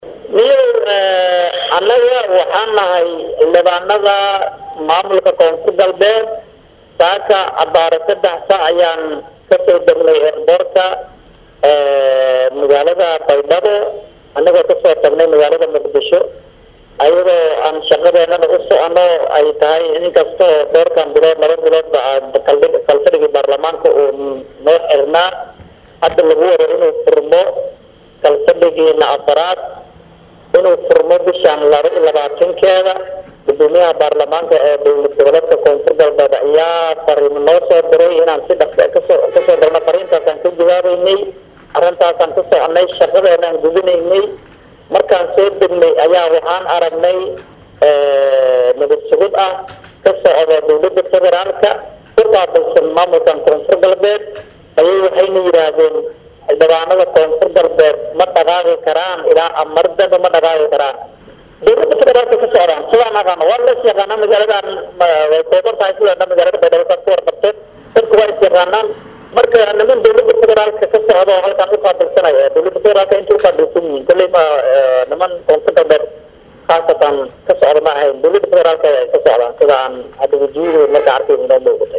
Dhageyso Xildhibaan ay xireen Ciidanka nabadsugida oo Xabsi uu ku jiro ka soo dhax hadlayo